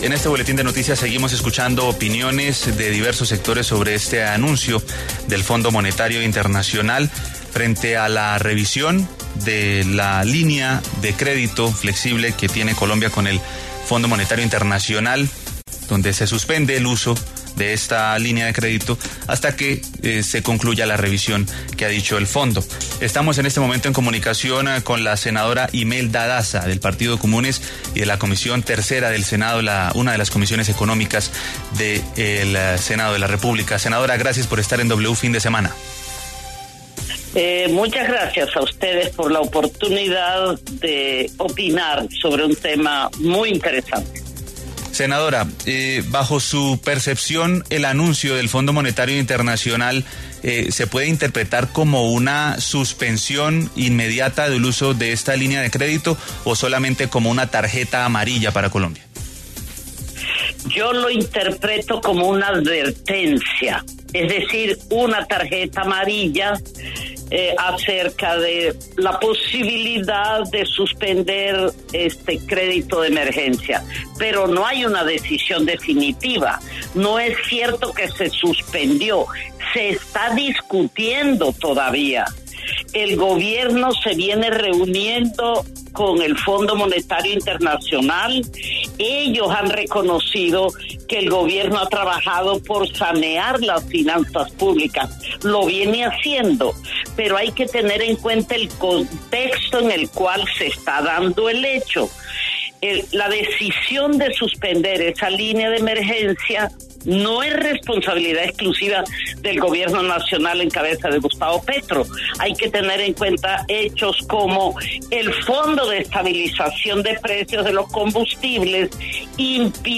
Imelda Daza, senadora del Partido Comunes, habló con W Fin De Semana luego de que el Fondo Monetario Internacional (FMI) suspendiera temporalmente la Línea de Crédito Flexible a Colombia.